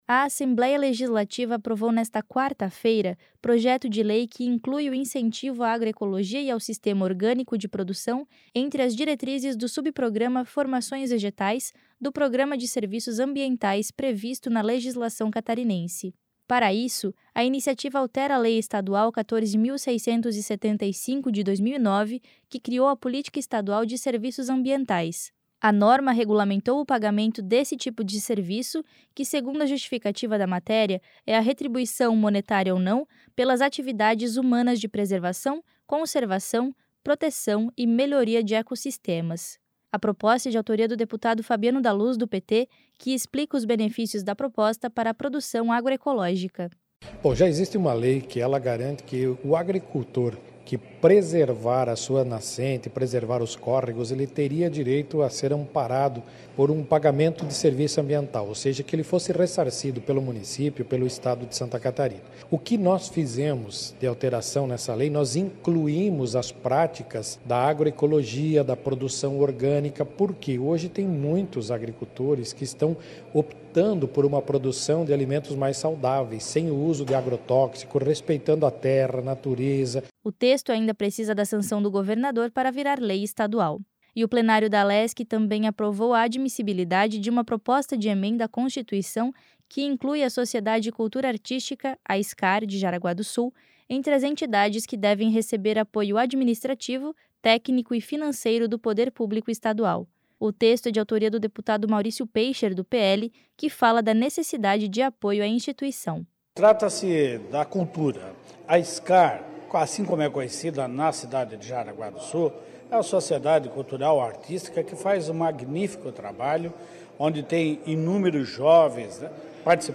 Entrevistas com:
- deputado Fabiano da Luz (PT), autor do projeto de lei;
- deputado Maurício Peixer (PL), autor da PEC.